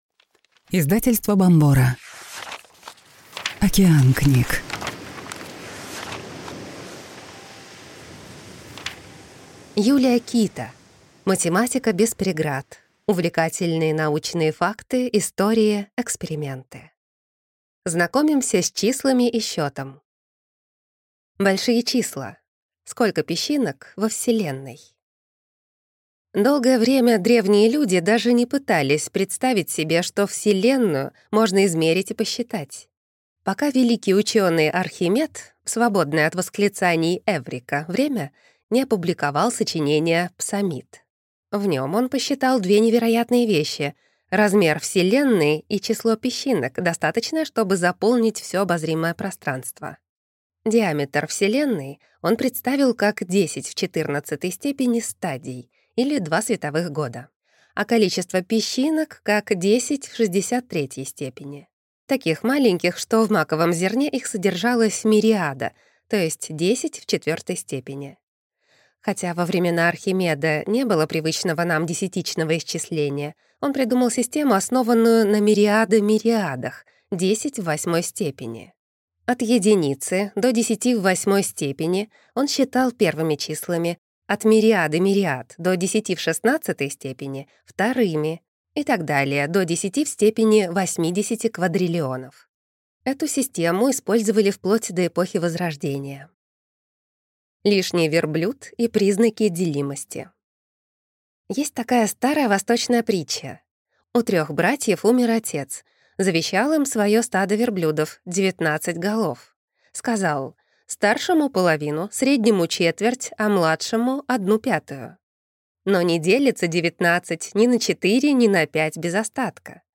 Аудиокнига Математика без преград. Увлекательные научные факты, истории, эксперименты | Библиотека аудиокниг